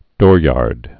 (dôryärd)